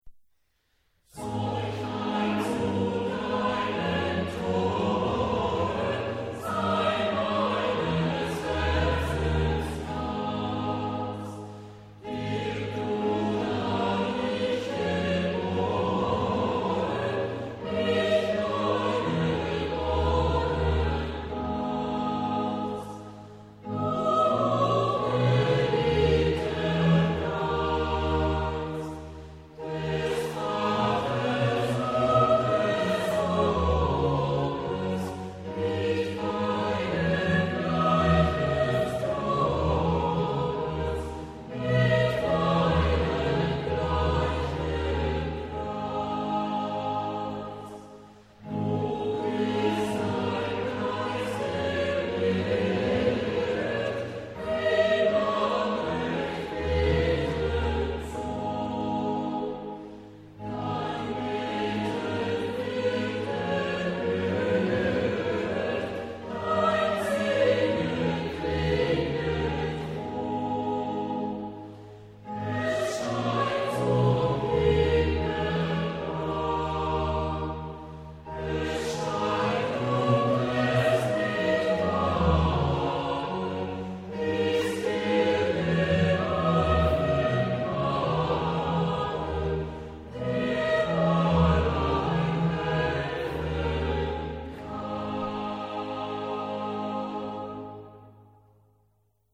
Maar er is Maar er is één belangrijke uitzondering: als op een opmaat aan het begin  (van een stuk of van een frase) een Ie trap wordt geplaatst, kan deze Ie trap op de eerste tel worden herhaald.